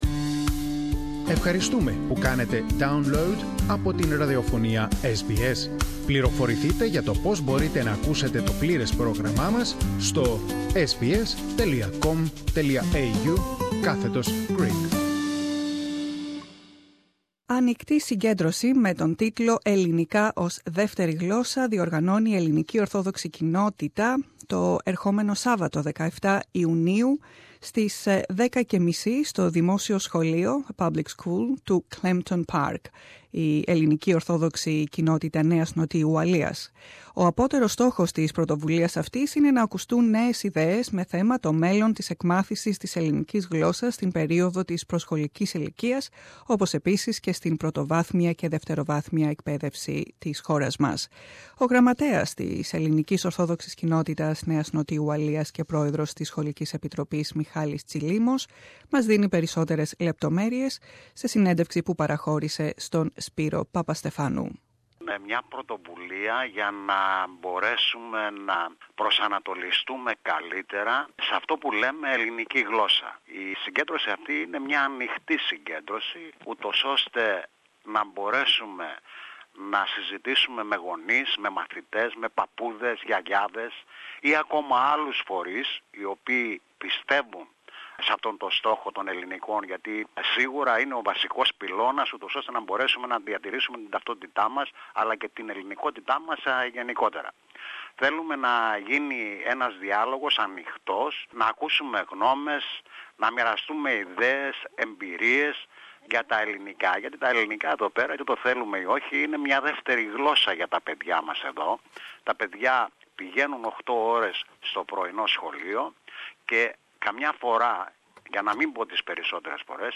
σε συνέντευξη